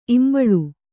♪ imbaḷu